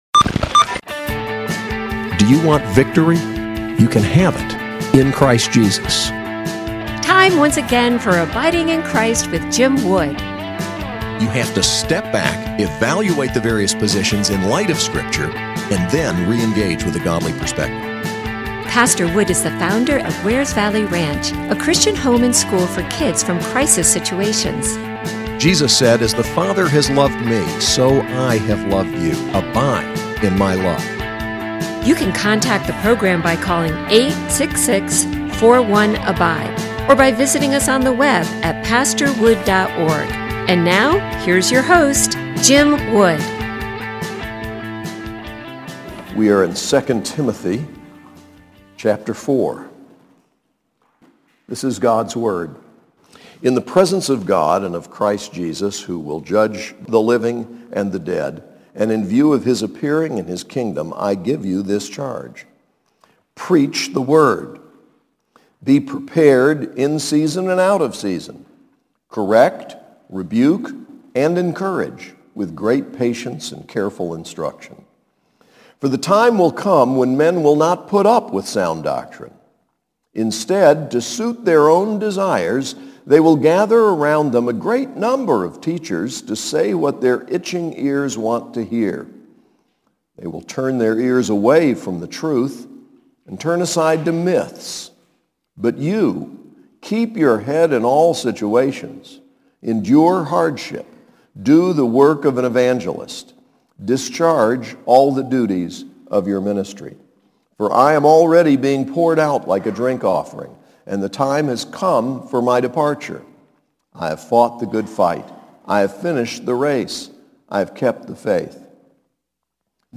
SAS Chapel: 2 Timothy 4